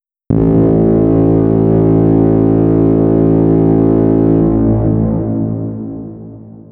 Horn.wav